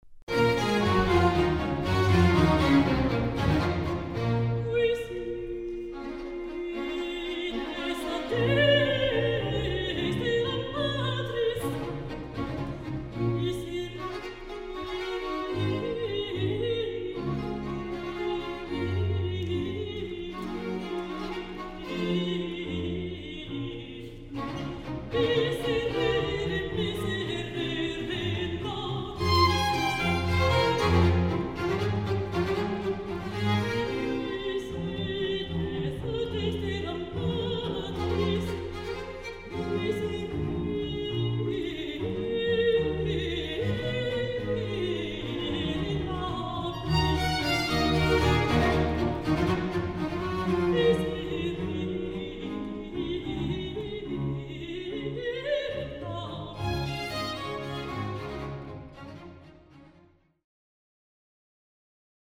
Baroque
Orchestre
Enregistré en concert par la Société Radio-Canada, 2007